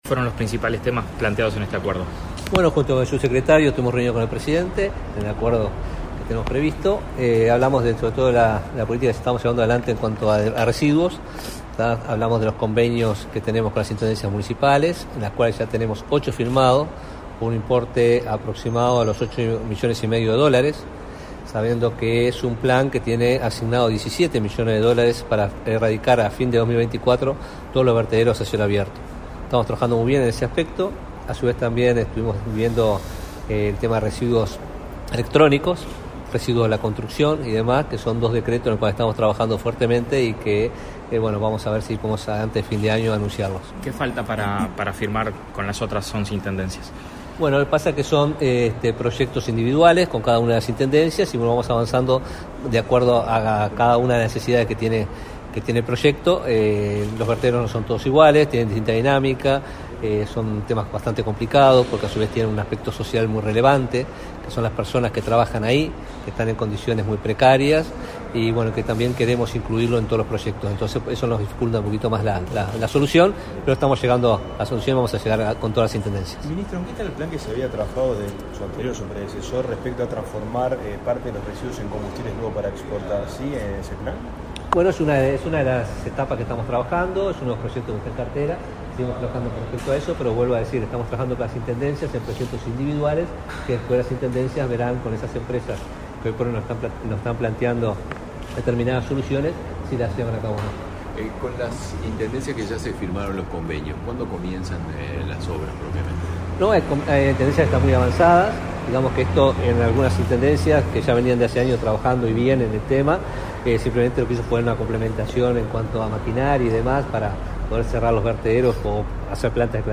Declaraciones a la prensa del ministro de Ambiente, Robert Bouvier
Declaraciones a la prensa del ministro de Ambiente, Robert Bouvier 09/08/2023 Compartir Facebook X Copiar enlace WhatsApp LinkedIn Tras participar en la reunión periódica con el presidente de la República, Luis Lacalle Pou, este 9 de agosto, el ministro de Ambiente, Robert Bouvier, realizó declaraciones a la prensa.